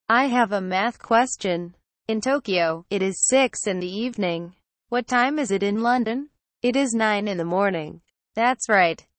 Conversation Dialog #2: